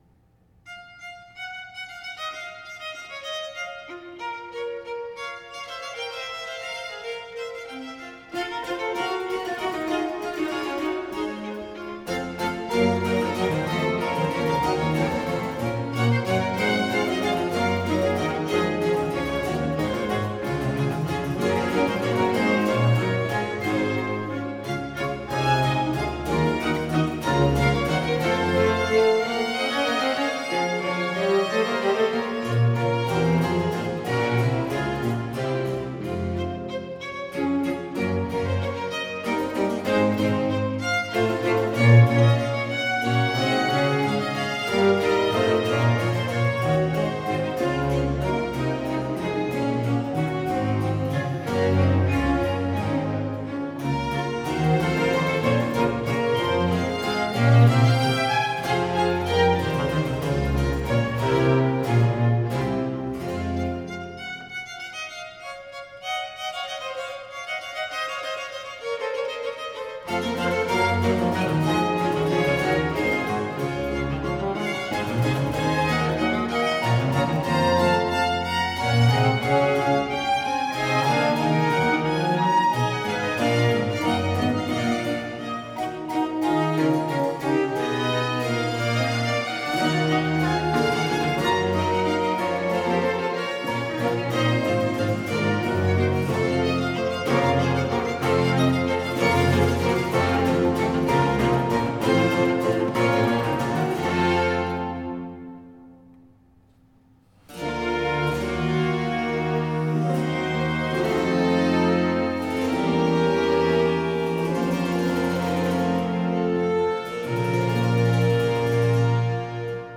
OLED SIIN ▶ muusika ▶ Klassika